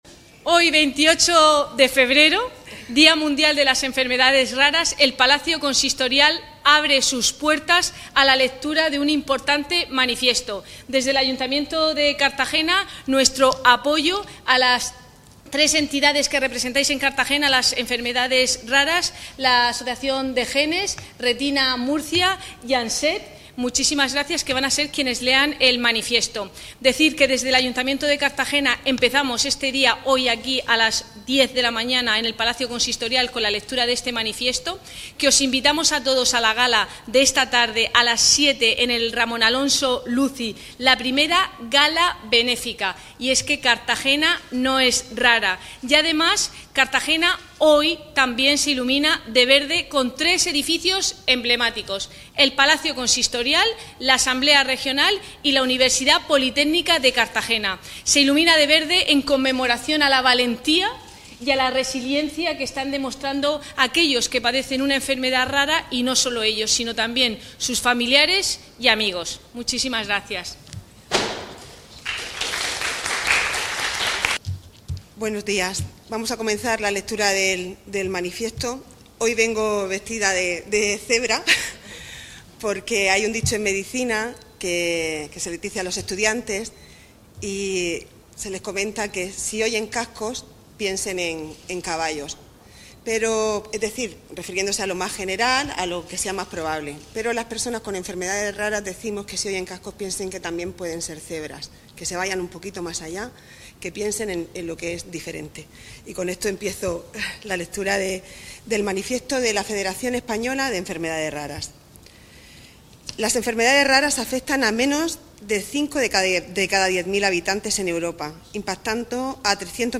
Enlace a Lectura del Manifiesto por el Día de las Enfermedades Raras.
En el Palacio Consistorial se ha leído un manifiesto en un acto en el que también ha participado la edil de Servicios Sociales, Cristina Mora.